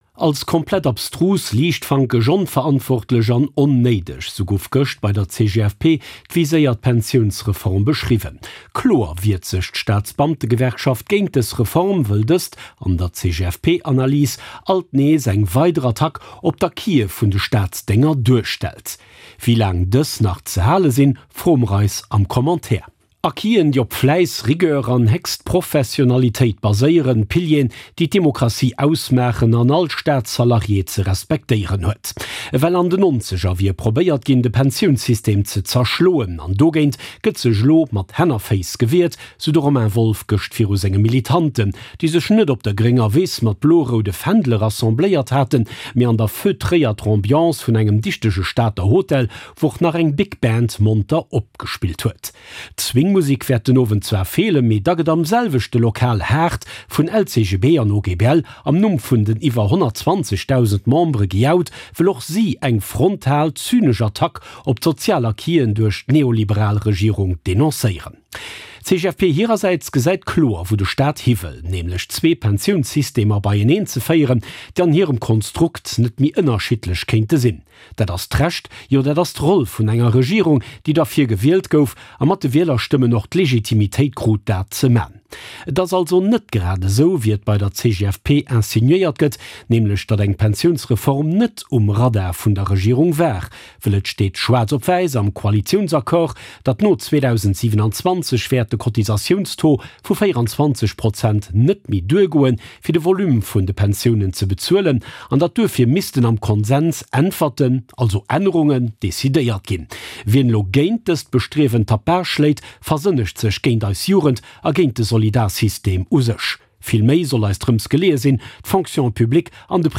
03/12/2024 ~ RTL - Commentaire Podcast